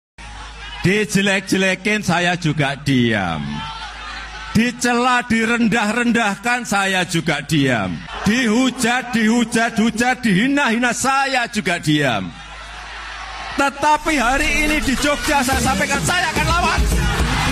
jokowi dulu saya diam Meme Sound Effect